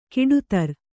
♪ kiḍutar